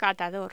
Locución: Catador
voz